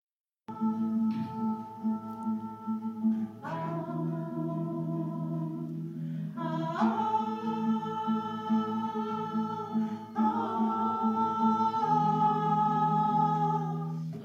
Les AH AAAH.... AH des sopranes